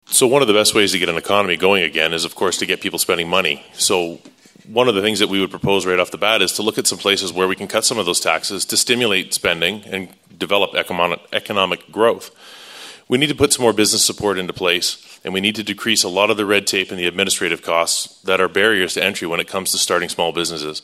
The Haldimand-Norfolk candidates spoke on the topic at the Royal Canadian Legion in Simcoe on Thursday night.